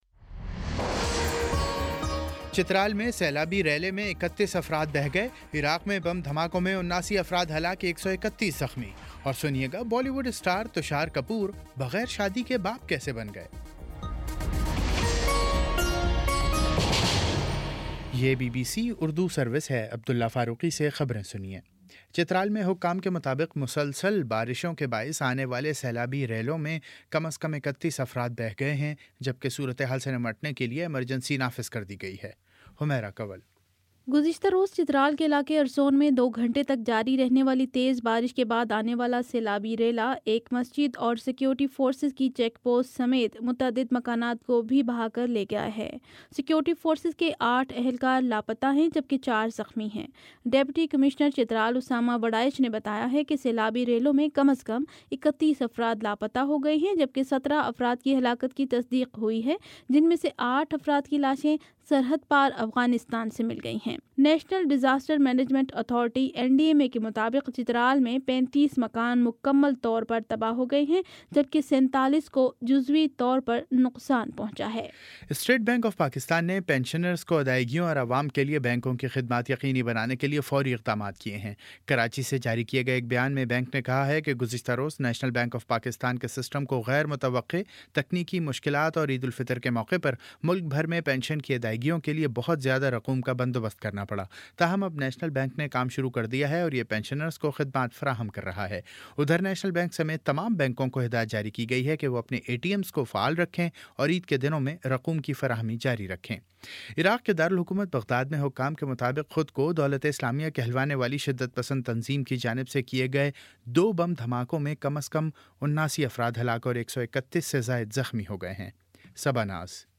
جولائی 03 : شام چھ بجے کا نیوز بُلیٹن